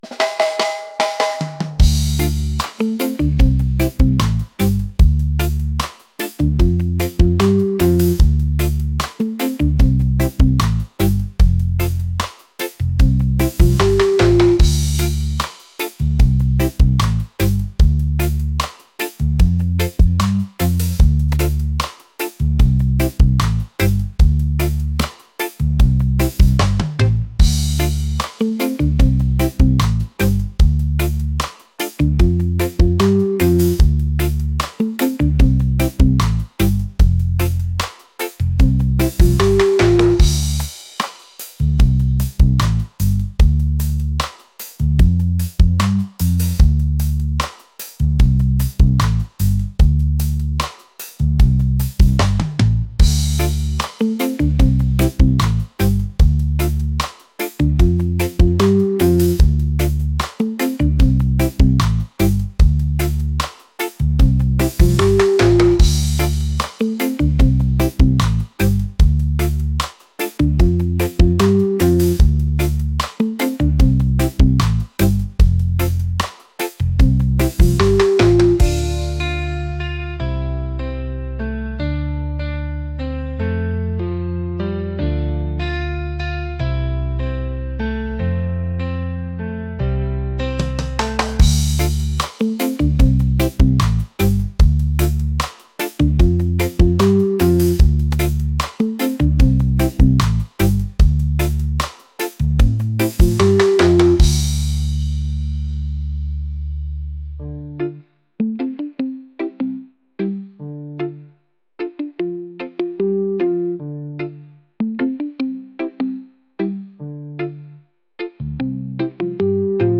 reggae | groovy | laid-back